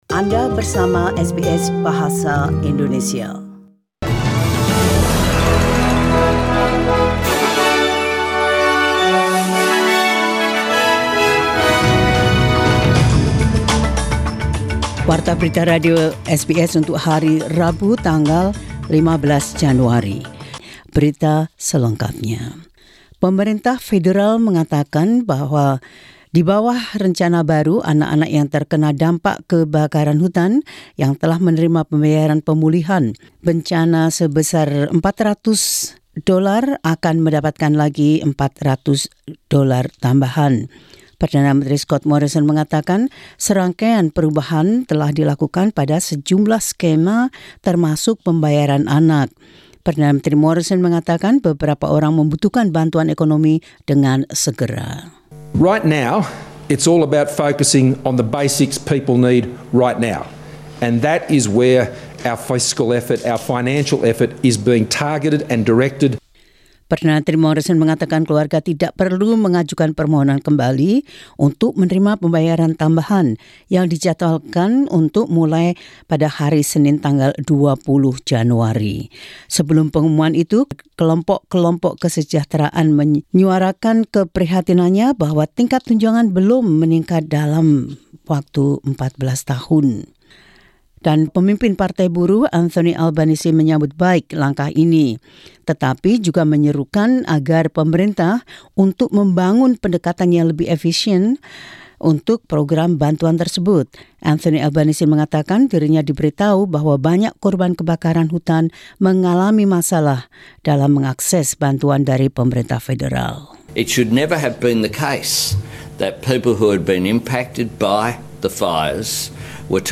SBS Radio News in Indonesian 15 Jan 2020.